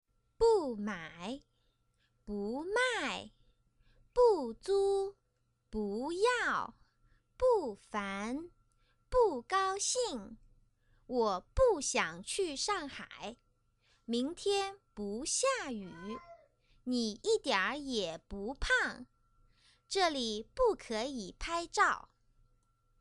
Check out the Task I uploaded for this lesson!Please ignore the "meow" in the backround :-D